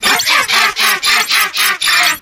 8bitvirus_kill_vo_03.ogg